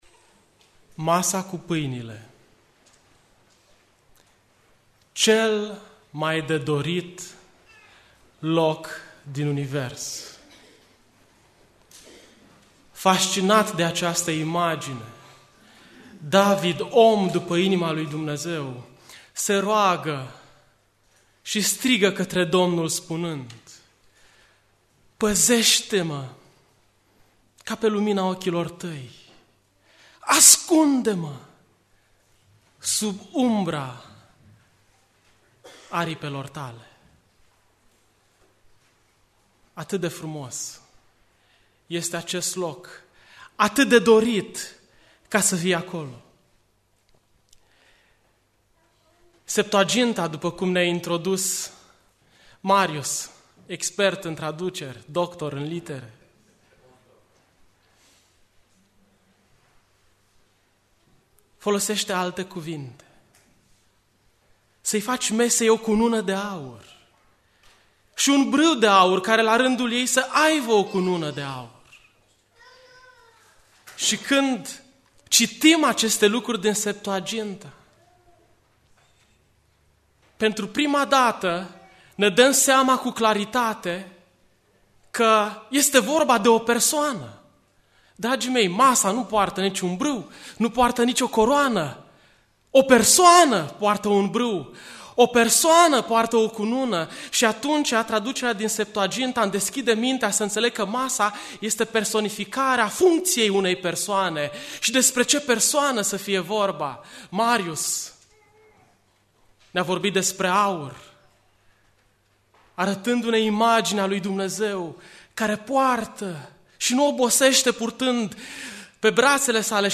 Predica Aplicatie Exod Masa cu paini